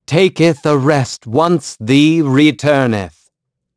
Siegfried-Vox_Victory.wav